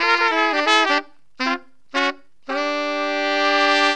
HORN RIFF 24.wav